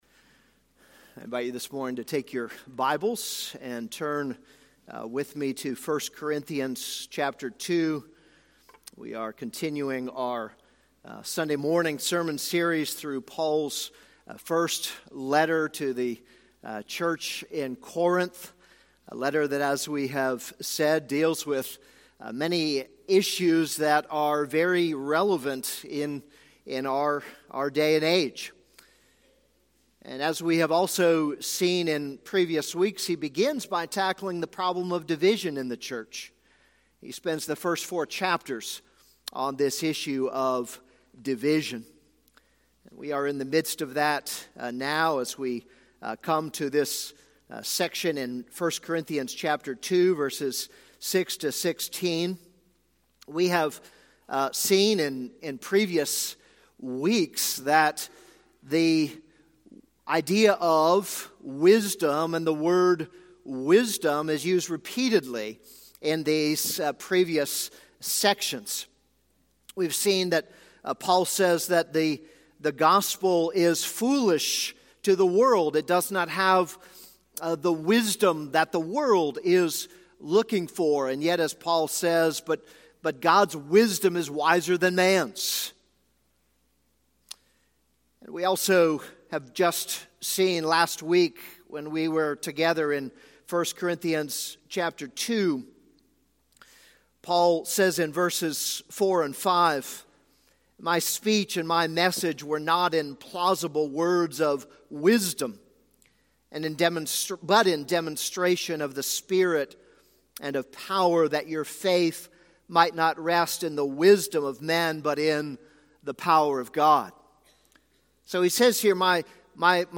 This is a sermon on 1 Corinthians 2:6-16.